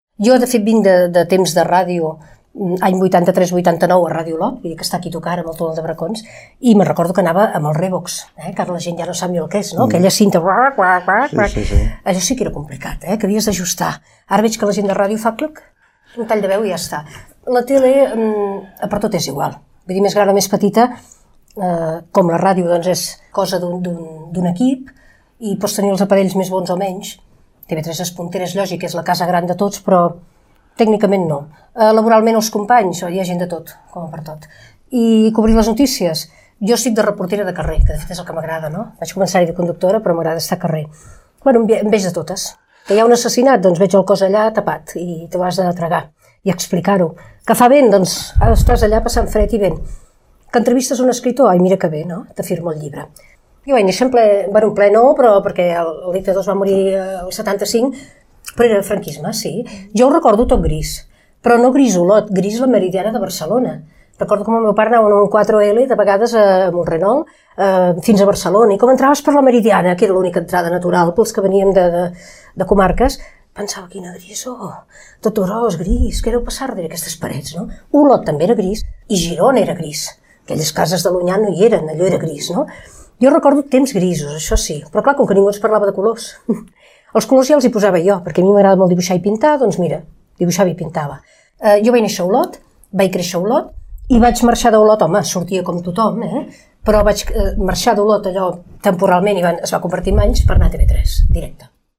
En companyia: entrevistada a Ràdio Vic, 2019
entrevista